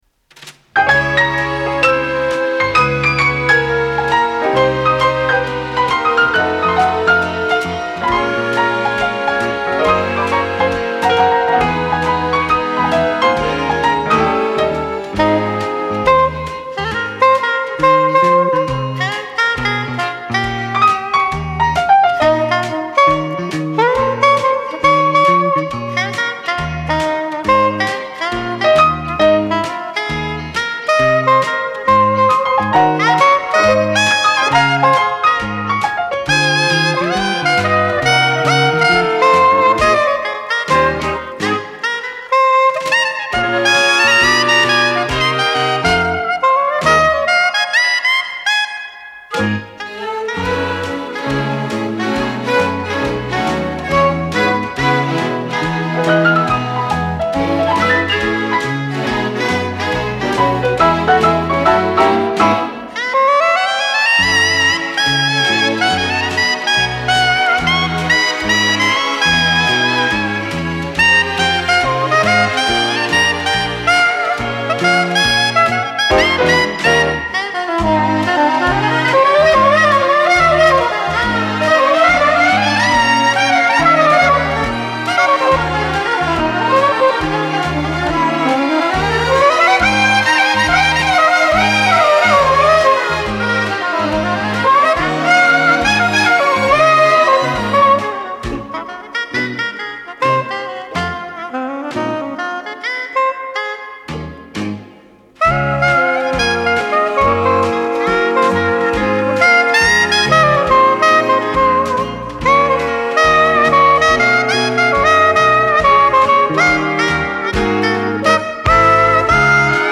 саксофон-сопрано